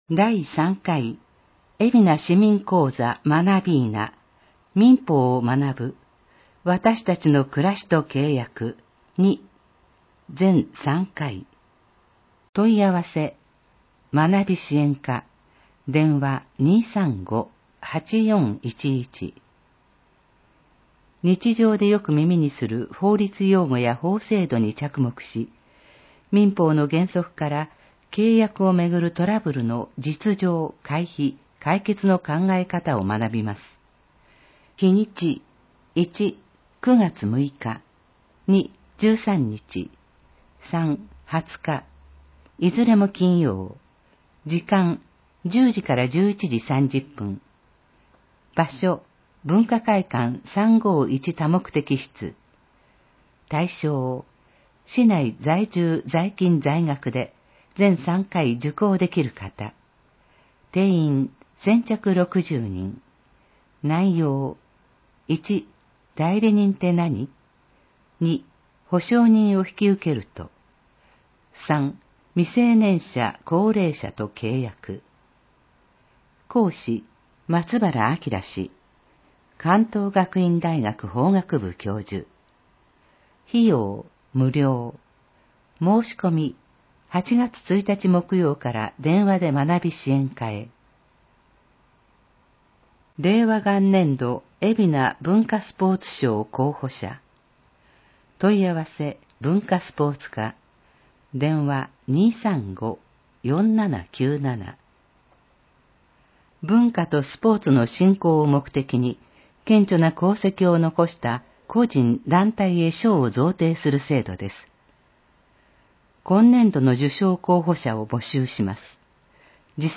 広報えびな 令和元年8月1日号（電子ブック） （外部リンク） PDF・音声版 ※音声版は、音声訳ボランティア「矢ぐるまの会」の協力により、同会が視覚障がい者の方のために作成したものを登載しています。